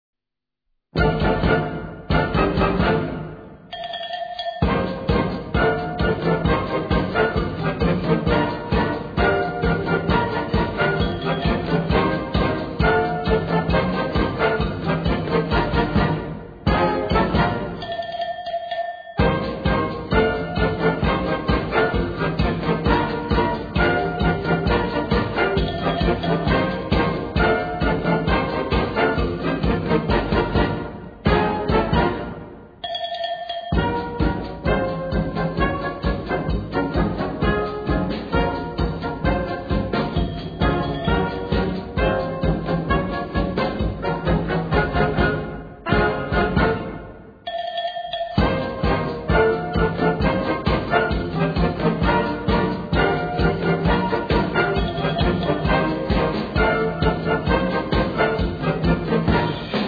Gattung: Cha-Cha-Cha
3:15 Minuten Besetzung: Blasorchester Zu hören auf